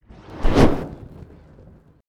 SFX_MAGIC_FIREBALL_001
fire fireball magic video-game sound effect free sound royalty free Nature